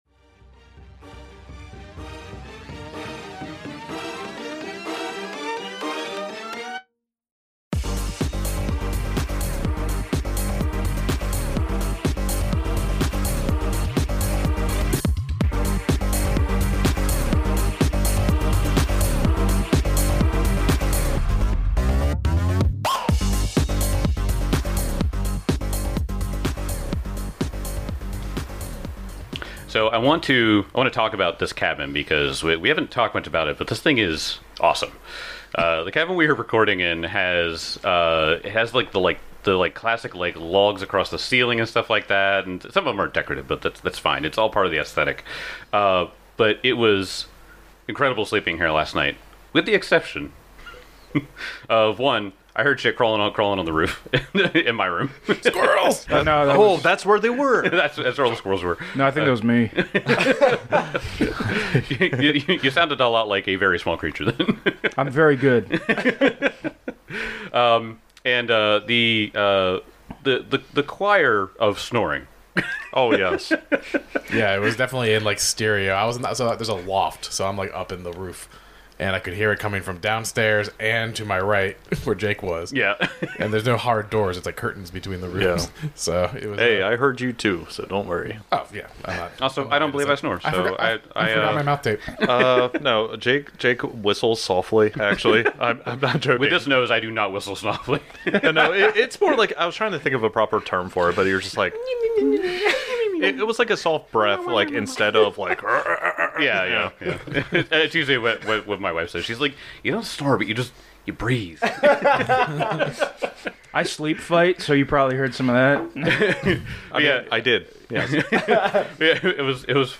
Actual play podcast of the Pathfinder 2e, Age of Ashes adventure path produced by Paizo. Five nerdy best friends who love to play pretend with dice!